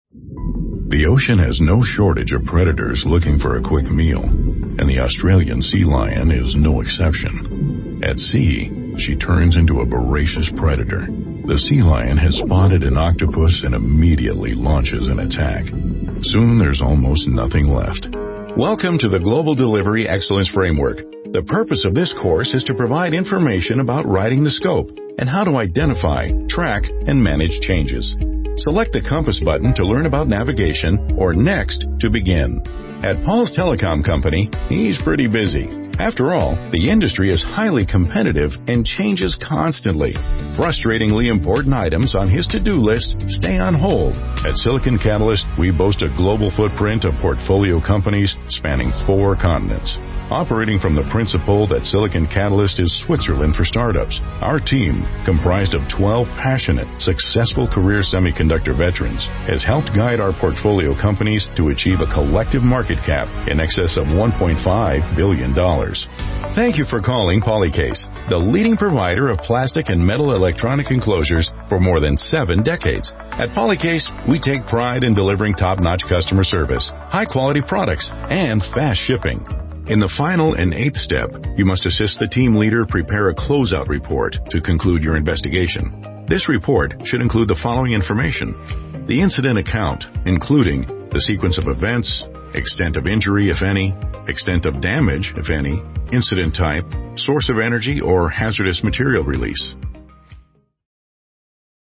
Male
English (North American)
Adult (30-50), Older Sound (50+)
It's authoritative without being intimidating — firm yet calming. There's a rugged, rustic charm that feels grounded and genuine, while its casual tone makes me approachable and relatable.
Television - Radio Commercials
Narration-Training Demo